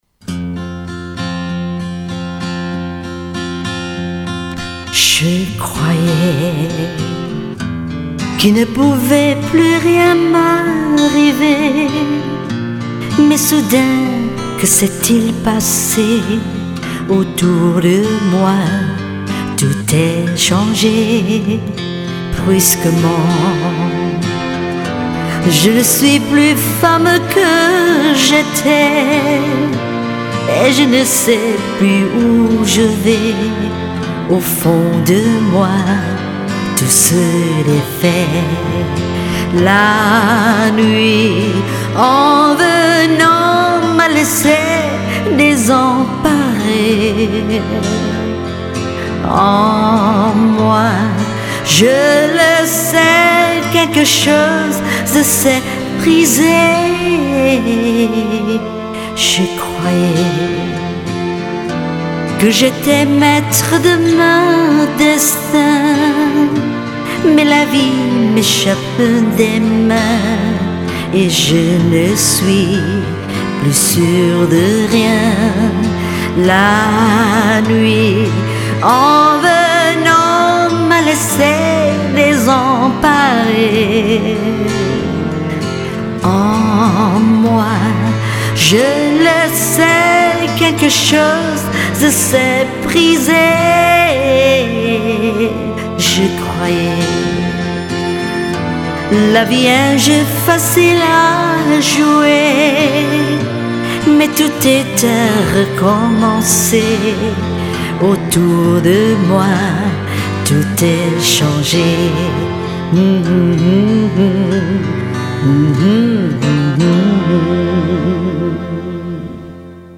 French version